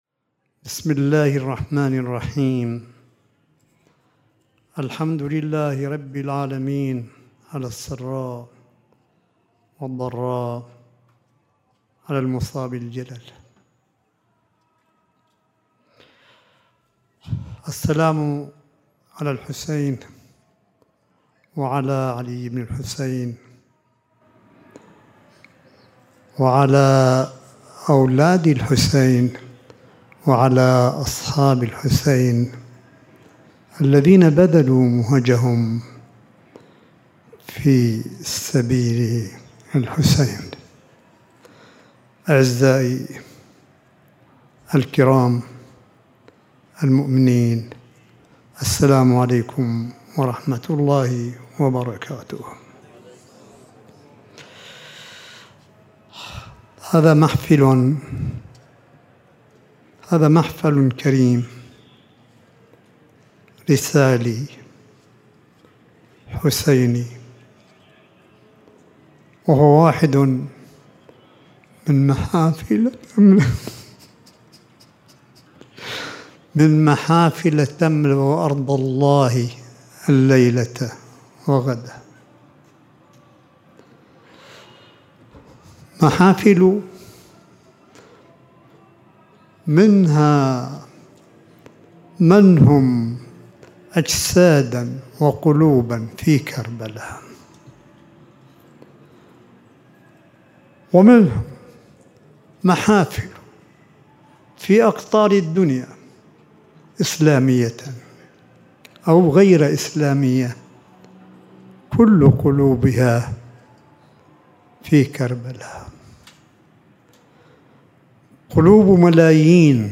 ملف صوتي لخطاب سماحة آية الله الشيخ عيسى أحمد قاسم ليلة العاشر من المحرم في قم المقدسة – 1443 هـ – 18 أغسطس 2021م